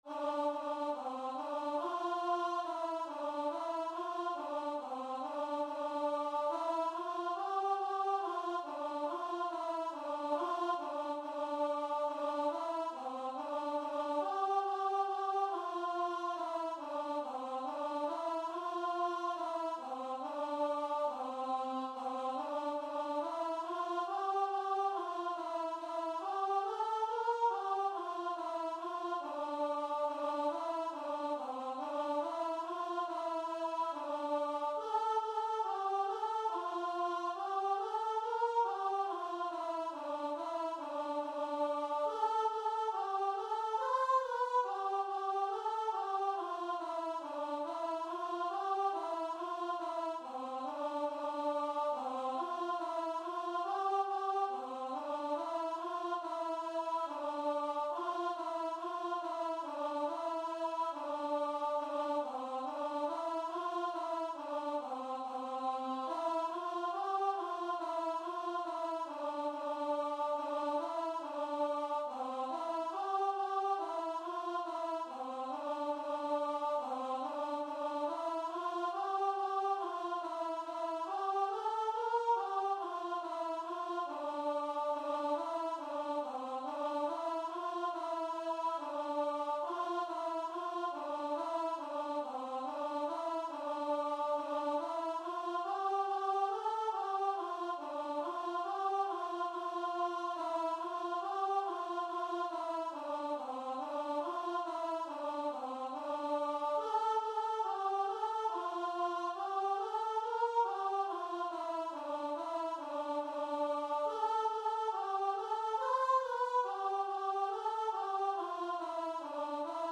Free Sheet music for Voice
C major (Sounding Pitch) (View more C major Music for Voice )
4/4 (View more 4/4 Music)
C5-C6
Christian (View more Christian Voice Music)